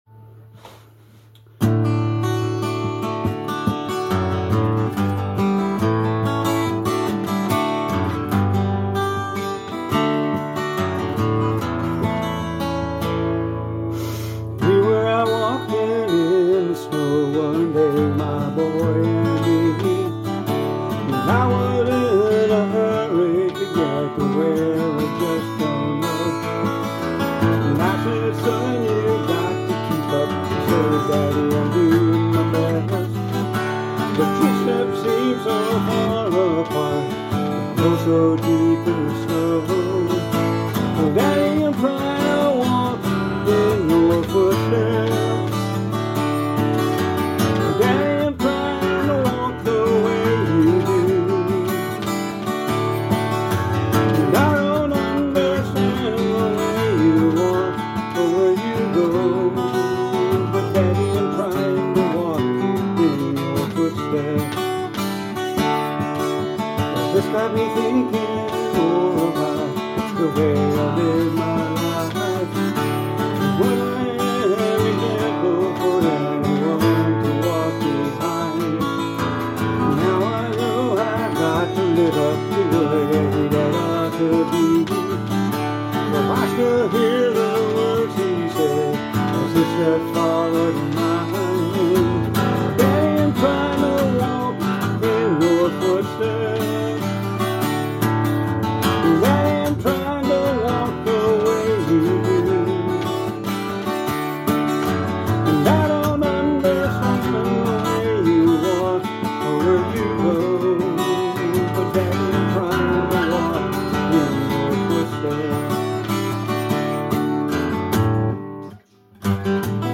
Christian Country